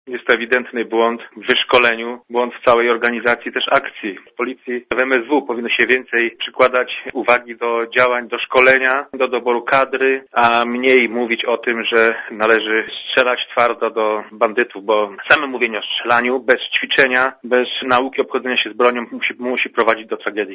To co się zdarzyło to ewidentny błąd w sztuce - powiedział Radiu ZET były szef MSWiA Marek Biernacki.
Mówi Marek Biernacki (80 KB)